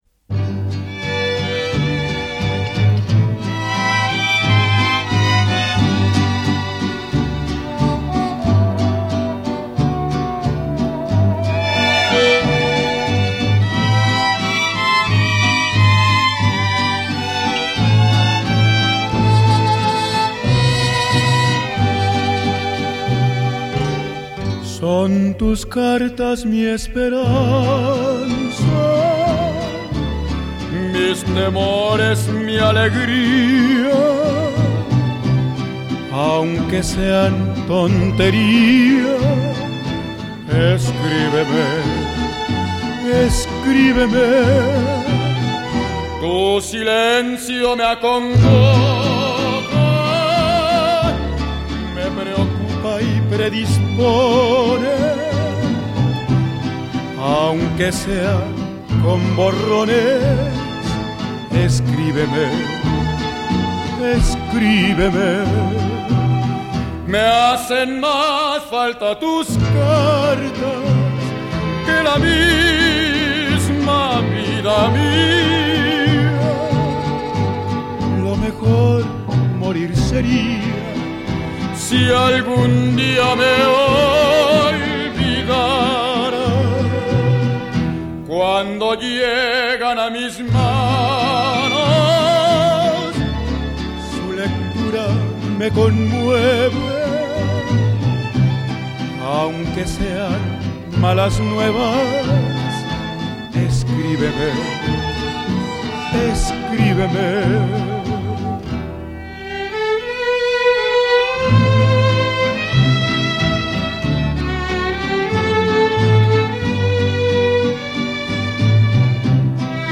Un bolero con mucha carne en su interior.